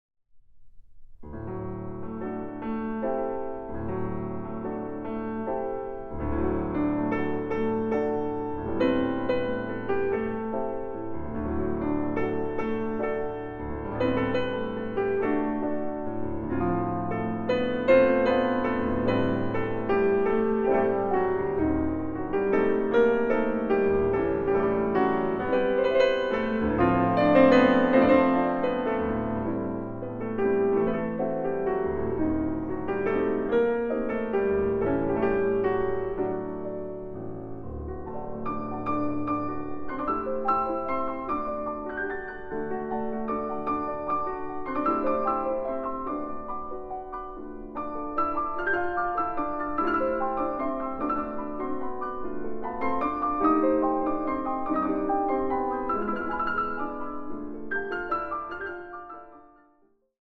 Recording: Mendelssohn-Saal, Gewandhaus Leipzig, 2024